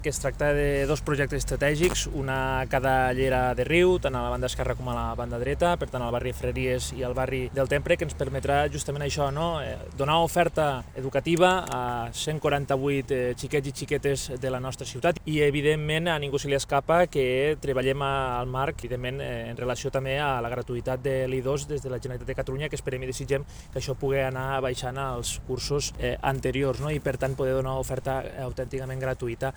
El regidor d’Educació, Víctor Grau, ha destacat la importància de garantir l’oferta de places públiques en l’educació infantil, especialment a l’etapa de 0 a 3 anys, on l’educació és gratuïta en centres públics.